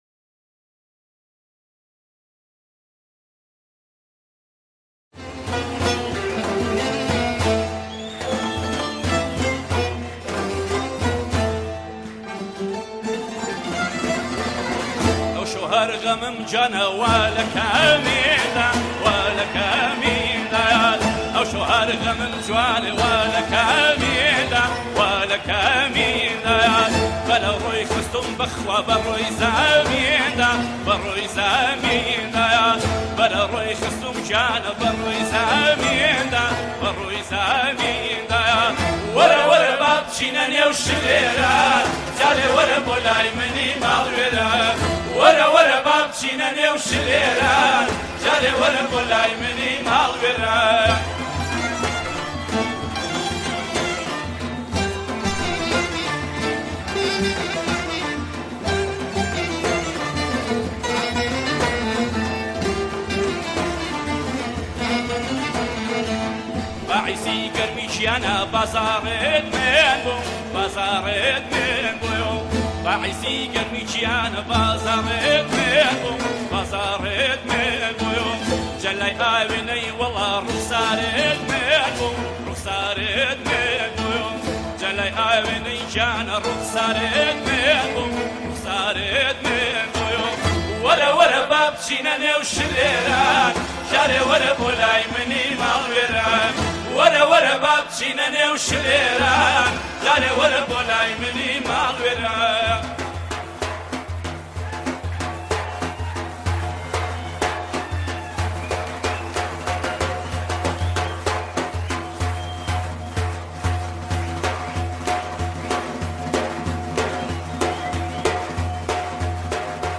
گوێ ڕادەگرین به گۆرانییەکی کوردی خۆش به ناوی وەره وەره